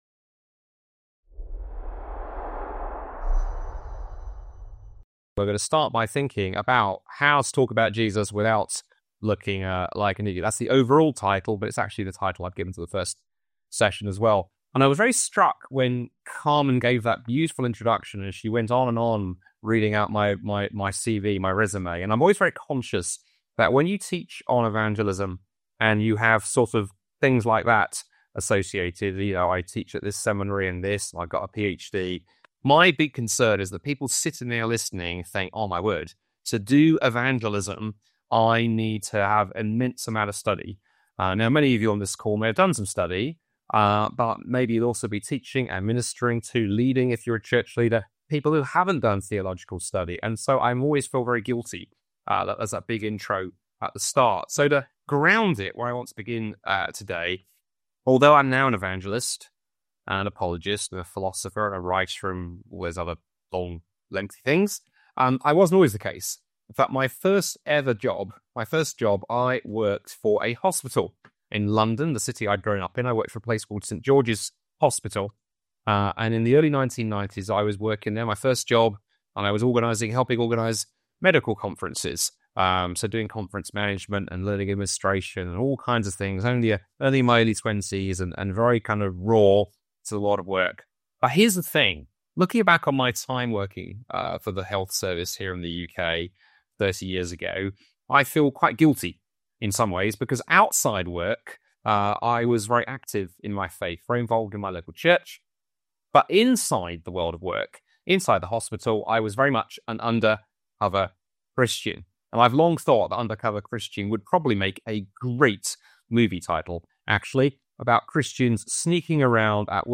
Event: Master Class